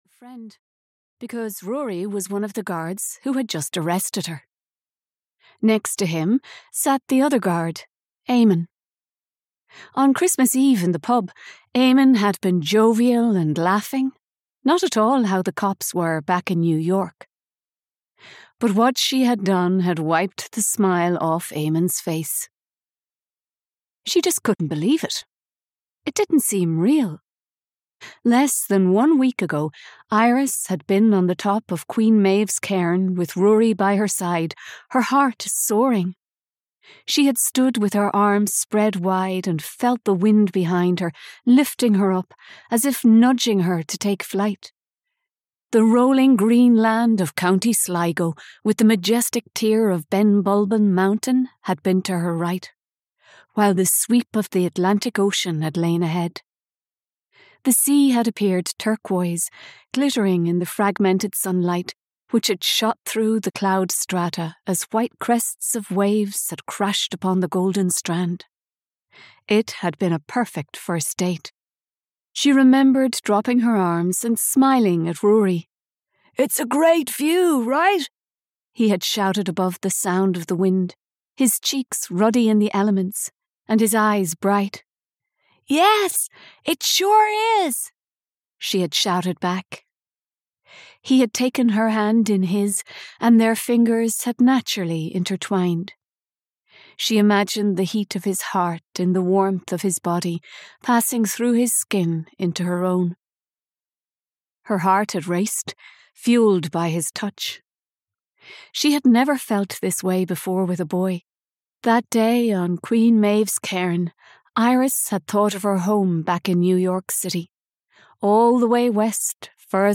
Audio knihaThe Last Summer in Ireland (EN)
Ukázka z knihy